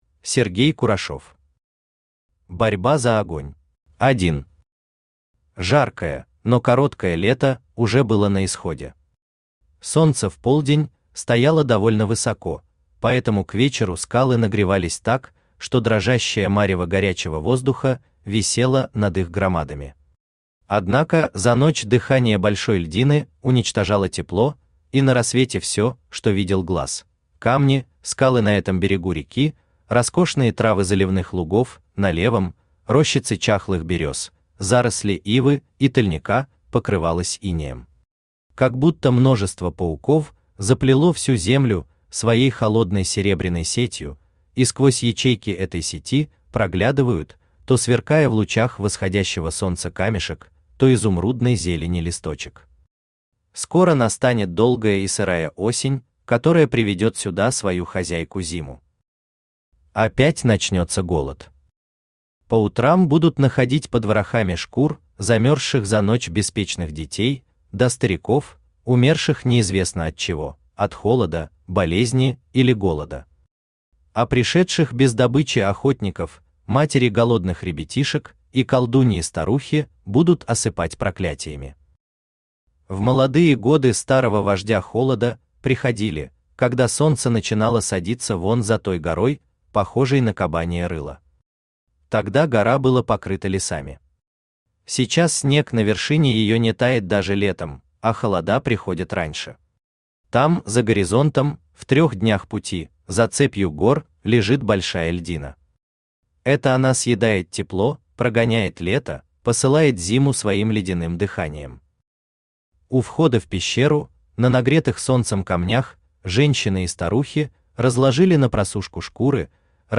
Аудиокнига Борьба за огонь | Библиотека аудиокниг
Aудиокнига Борьба за огонь Автор Сергей Павлович Курашов Читает аудиокнигу Авточтец ЛитРес.